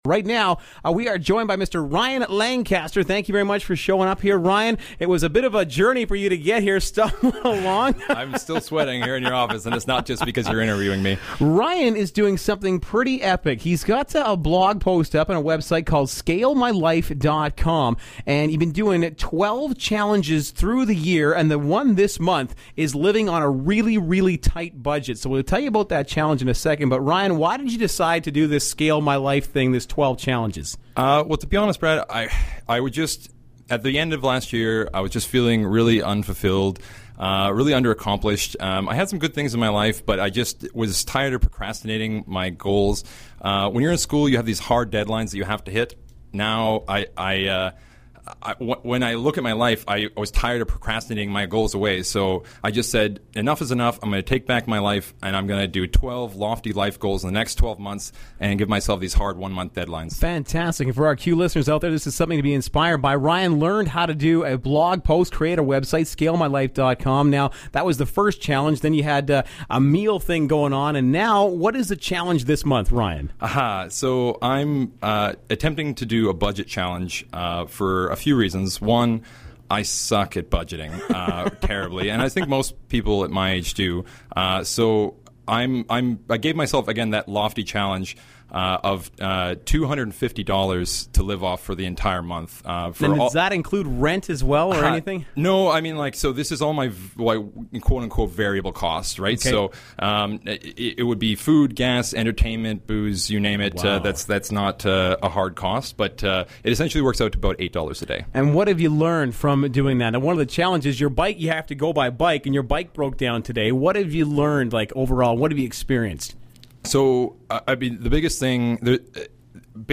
By the time I got to the studio I was exhausted; I was overheating in my dress clothes, I was out of breath and I was sweating profusely. Within minutes I was on the air and doing my best to sound coherent and comprehensible.